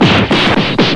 KImnmzQWCiW_DBZ-prepunch1.wav